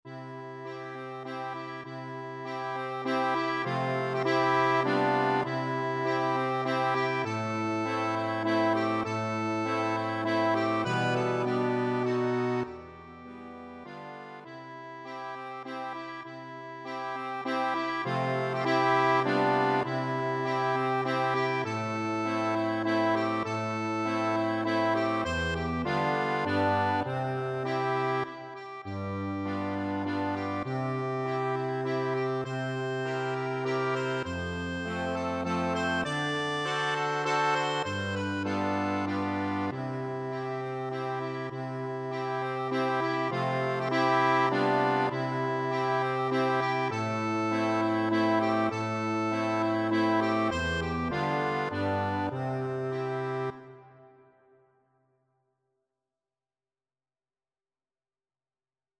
It is written in a simple waltz style.
C major (Sounding Pitch) (View more C major Music for Accordion )
Moderato
3/4 (View more 3/4 Music)
Accordion  (View more Intermediate Accordion Music)
Classical (View more Classical Accordion Music)
brahms_waltz_ACC.mp3